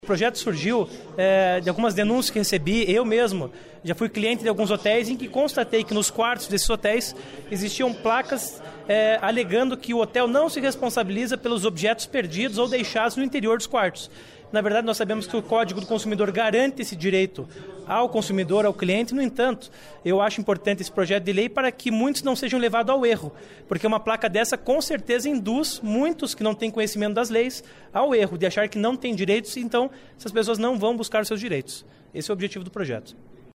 projeto do deputado felipe Francischini (SD) prevê que hotéis sno estado são obrigados a informar que se responsabilizam por objetos pessoais dos hóspedes, por meio de cartazes. Ouça entrevista com o parlamentar.